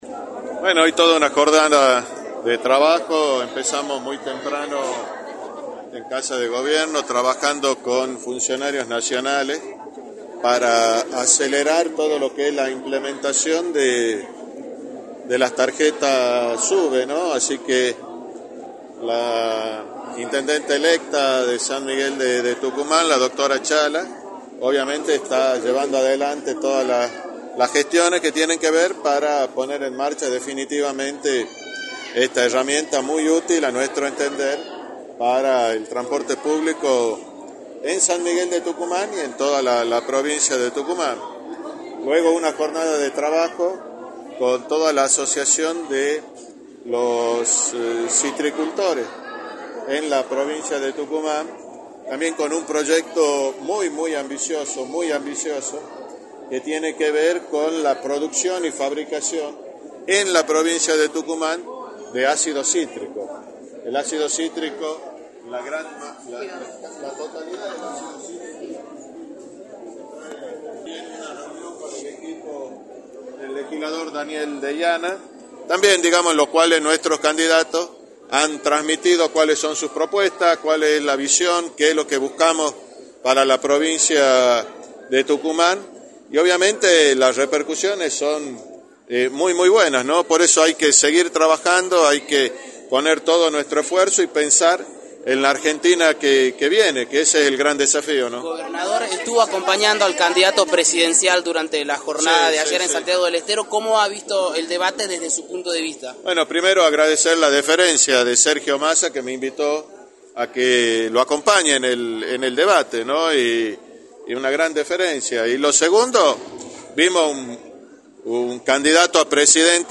Juan Manzur, Gobernador, estuvo presente acompañando a los candidatos a Diputados Nacional, Pablo Yedlin y Gladys Medina y analizó en Radio del Plata Tucumán, por la 93.9, las repercusiones del debate presidencial realizado en Santiago del Estero.
«Vimos a un candidato sólido, impecable, con un plan de trabajo, con equipos y por no tengo dudas que Sergio Massa va a ser el próximo Presidente» señaló Juan Manzur en entrevista para «La Mañana del Plata», por la 93.9.